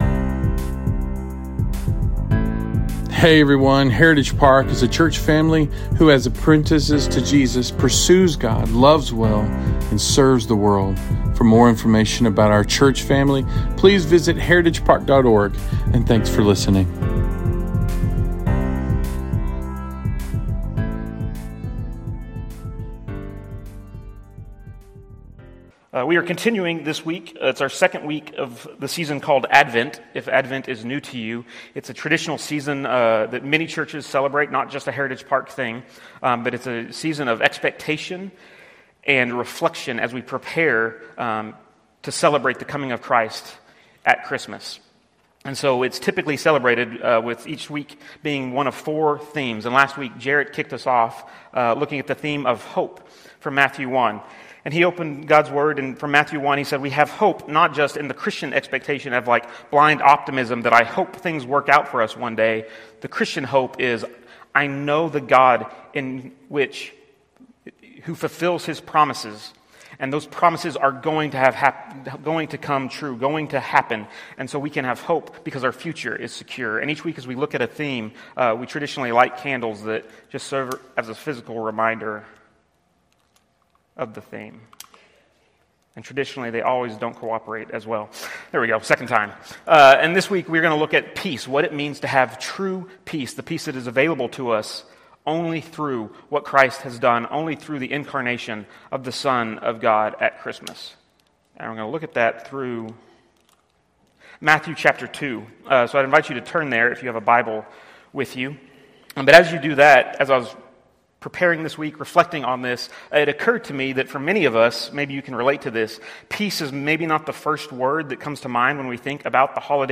Sermons from Heritage Park Baptist Church in Webster, TX
Heritage Park Baptist Church Sermons